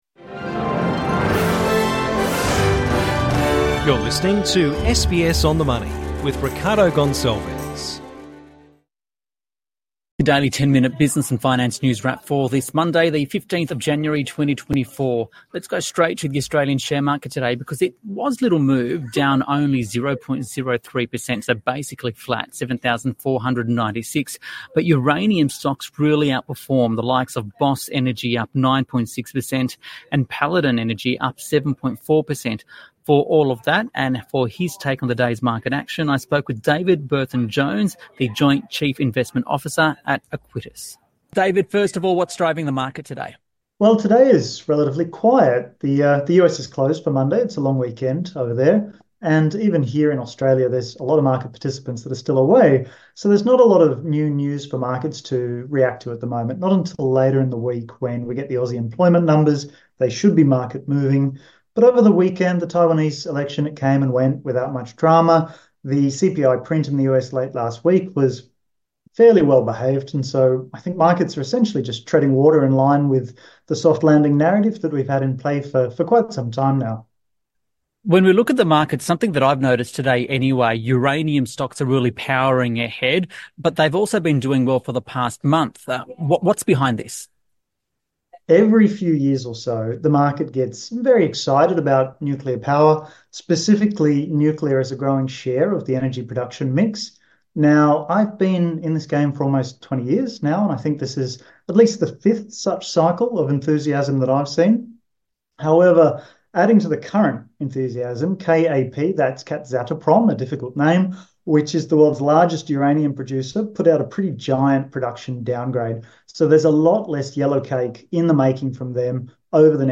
It's your daily 10 minute business and finance news wrap for this Monday the 15th of January, 2024.